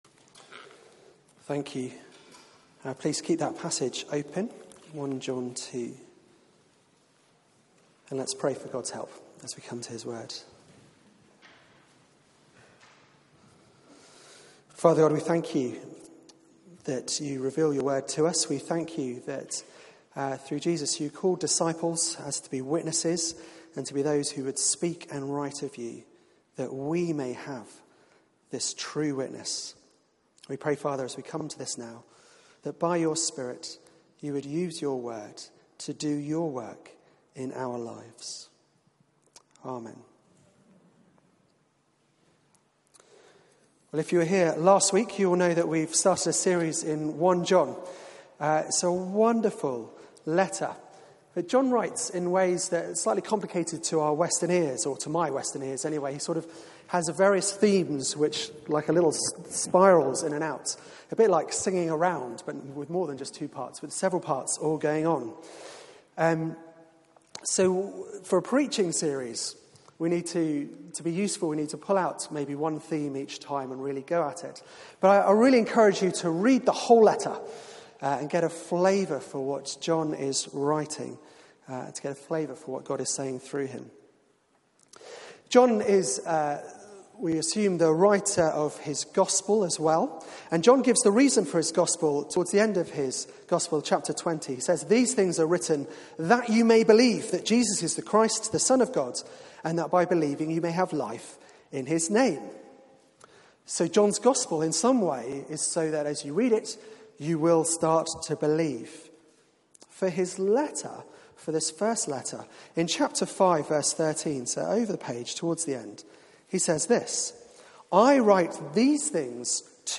Media for 4pm Service on Sun 15th Apr 2018 16:00 Speaker
Series: Know you have Eternal Life Theme: Threats to Assurance Sermon